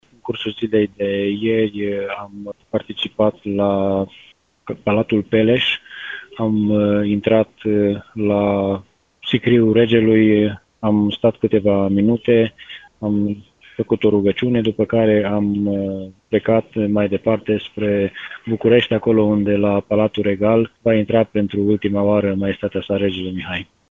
Este vorba de reprezentanţi ai primăriei, liceului şi de pe Domeniul Regal Săvârşin, spune primarul comunei, Ioan Vodicean.